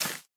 Minecraft Version Minecraft Version latest Latest Release | Latest Snapshot latest / assets / minecraft / sounds / block / cave_vines / break3.ogg Compare With Compare With Latest Release | Latest Snapshot
break3.ogg